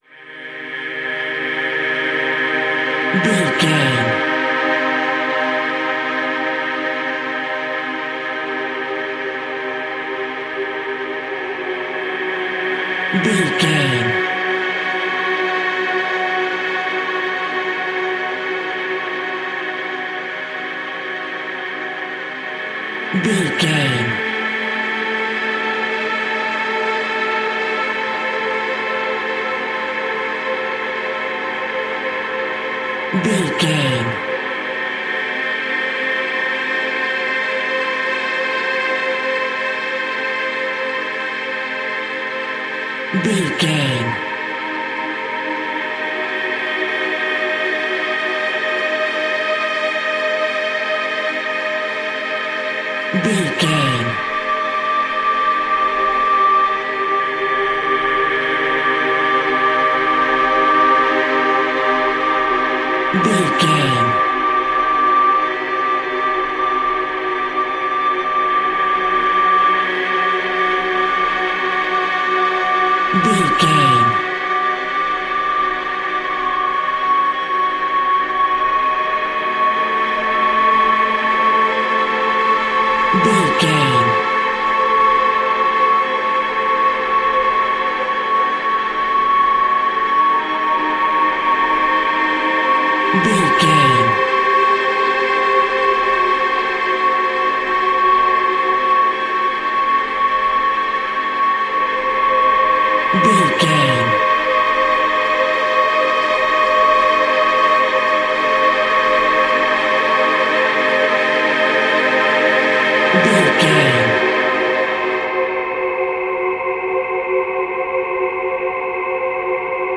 Scary Woman.
Aeolian/Minor
Slow
scary
ominous
eerie
synthesiser
strings
horror music
Horror Pads